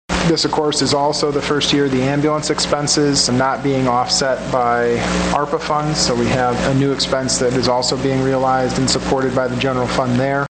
Sturgis City Manager Andrew Kuk speaks to the Sturgis City Commission during Wednesday's meeting
City Manager Andrew Kuk says this year’s budget had some unexpected challenges and expenses. One of them was the situation surrounding the Sturgis Hospital along with another matter.